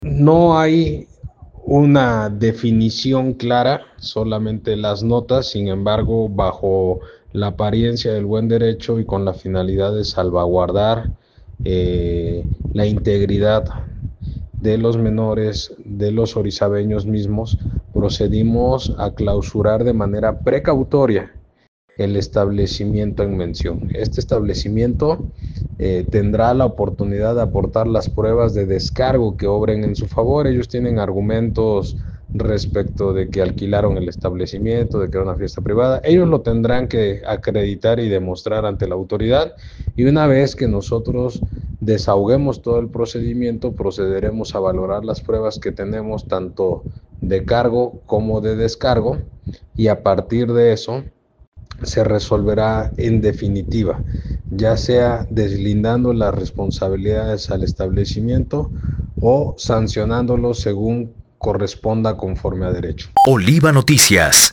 “No hay una definición clara de lo sucedido, sólo lo que circula en redes, sin embargo, bajo la apariencia del buen derecho y con la finalidad de salvaguardar la integridad de los menores y de los orizabeños mismos, procedimos a clausurar de manera precautoria el establecimiento”, explicó el funcionario en entrevista.